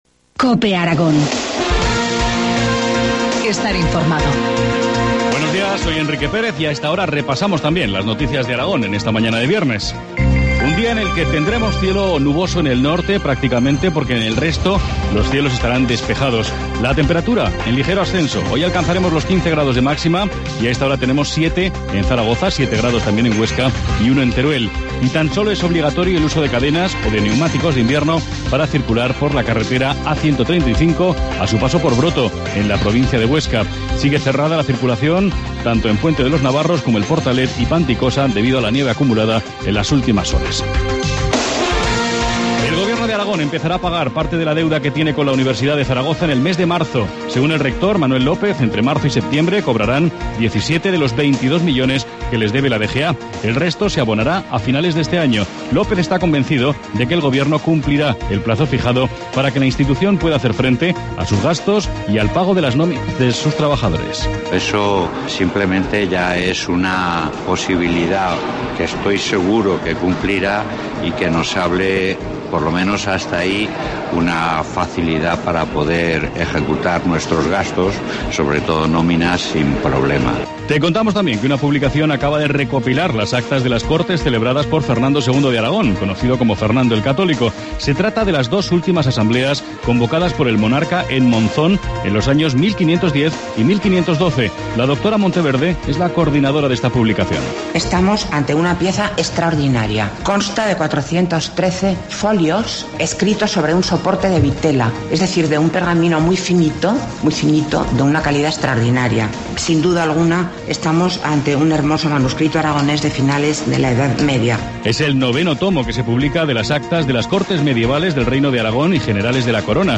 Informativo matinal, viernes 15 de febrero, 8.25 horas